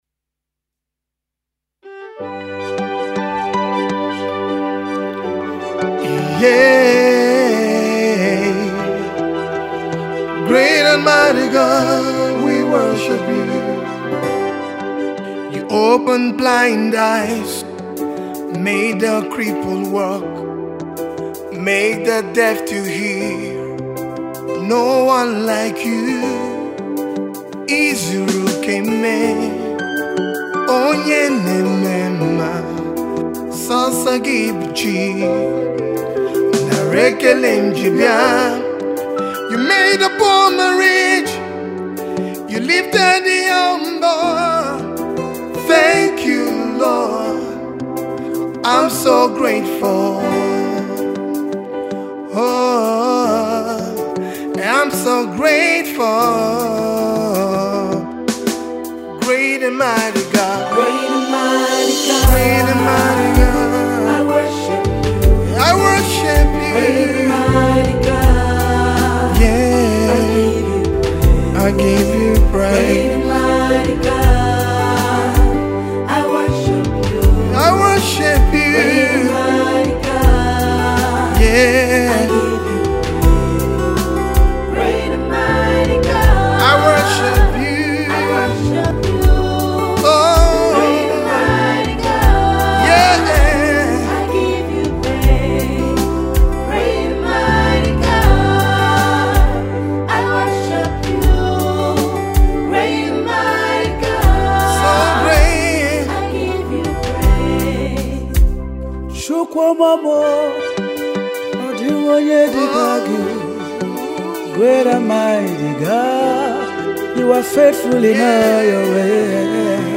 Nigerian Gospel singer